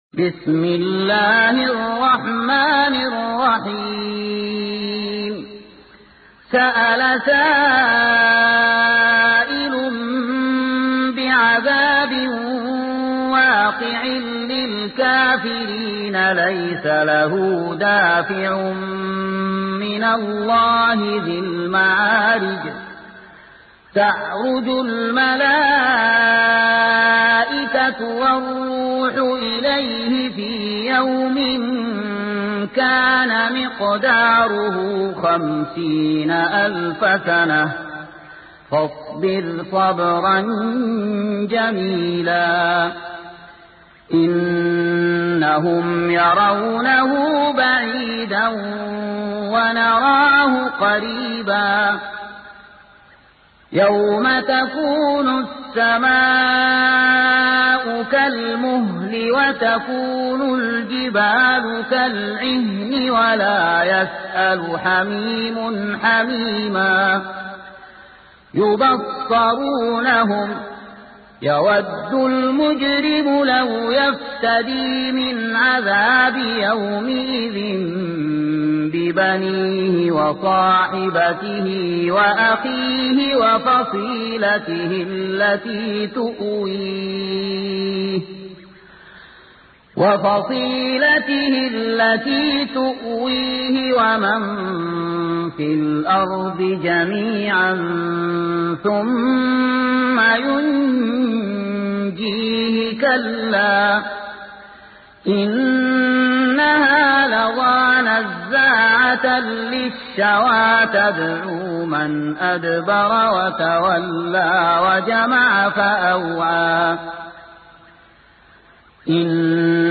سورة المعارج مكية عدد الآيات:44 مكتوبة بخط عثماني كبير واضح من المصحف الشريف مع التفسير والتلاوة بصوت مشاهير القراء من موقع القرآن الكريم إسلام أون لاين